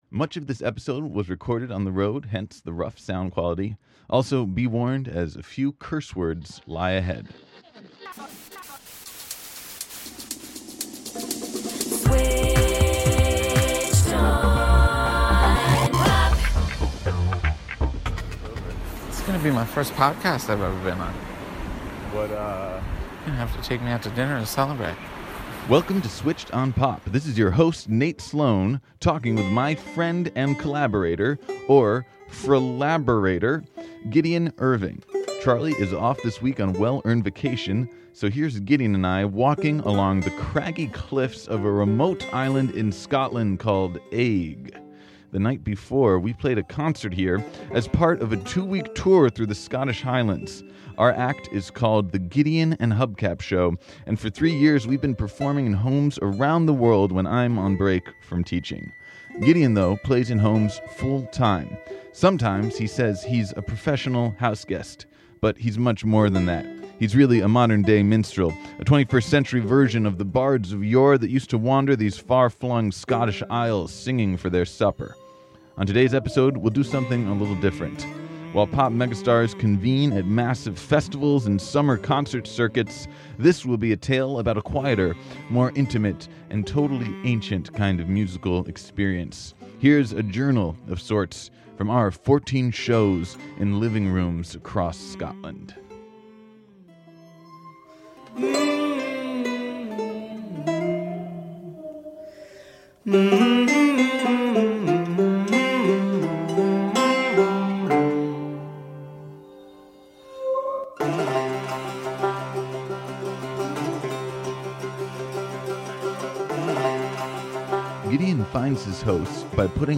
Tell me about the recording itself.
" takes us on a home-show tour of the Scottish Highlands with his traveling minstrel act